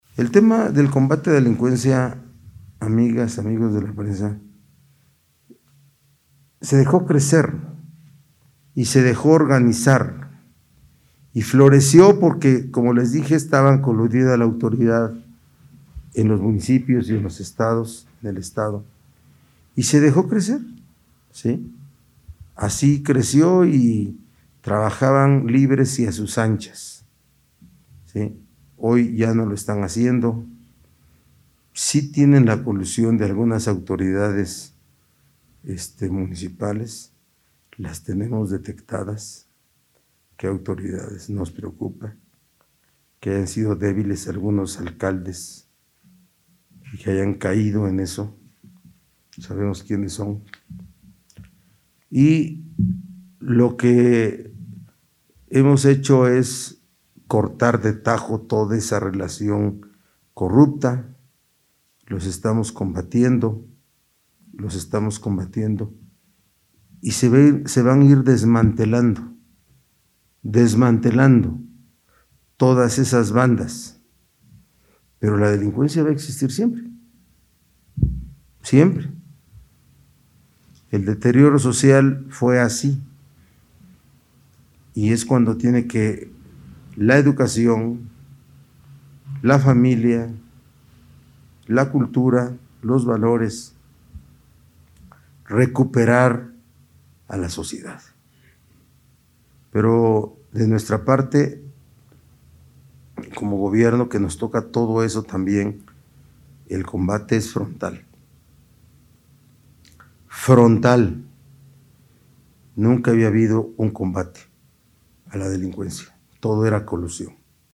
Lo anterior lo expuso en la rueda de prensa que encabeza para actualizar el panorama epidemiológico de Puebla, en la que resaltó que, además de estas acciones, su administración combate la inseguridad con educación y cultura, con el objetivo de recuperar el tejido social.